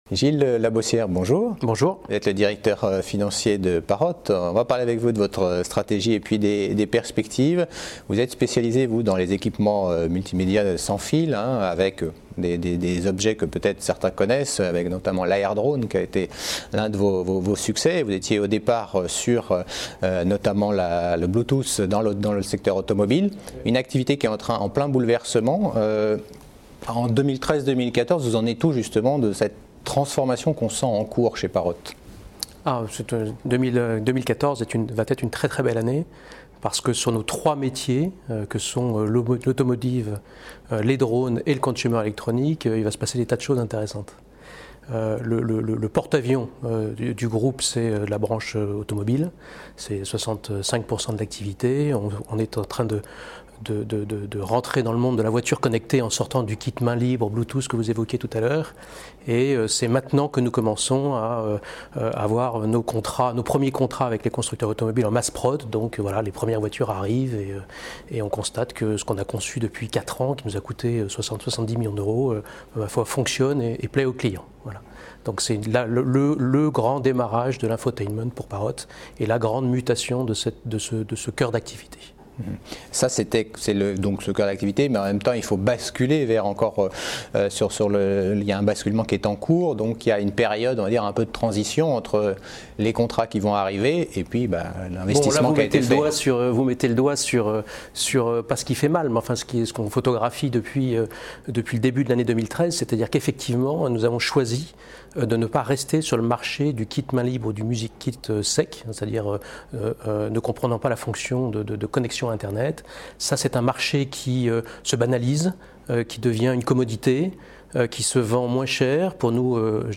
Rencontre à l'European Small Cap Event du spécialiste de la technologie mobile sans fil